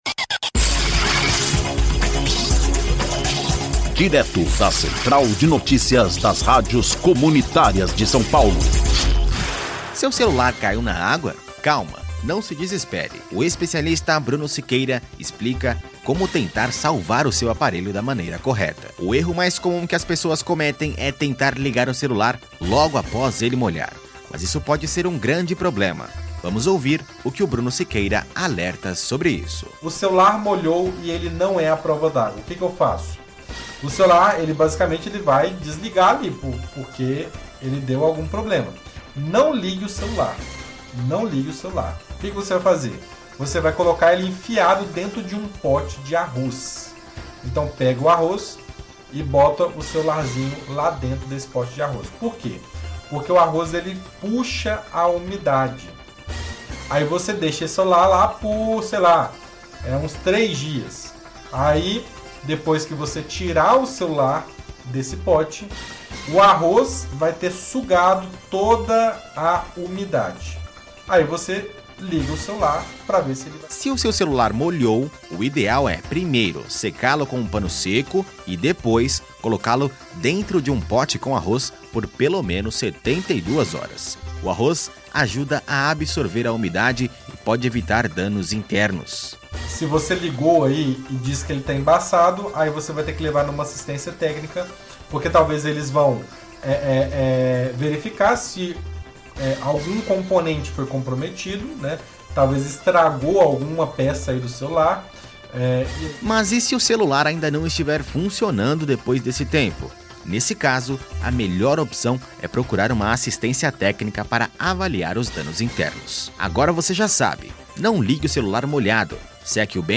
Ouça a notícia: Celular caiu na água? Saiba o que fazer!